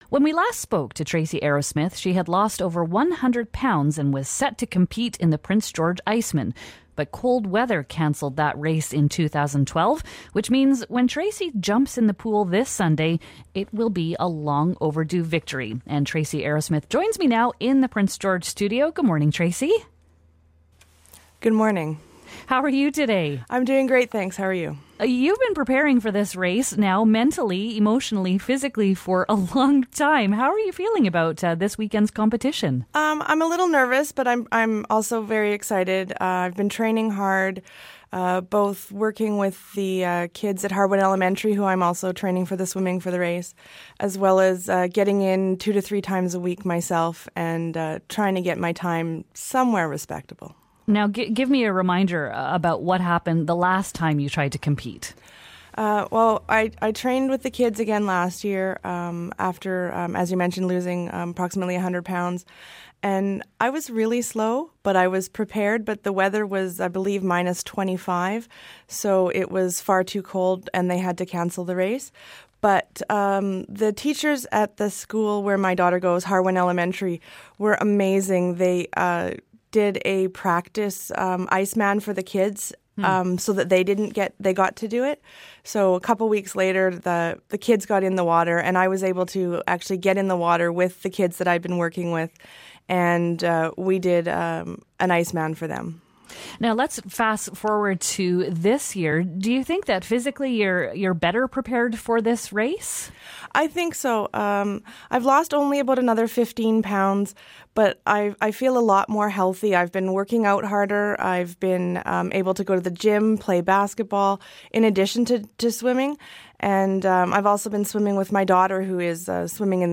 She's back at it again and she was our guest in studio this morning.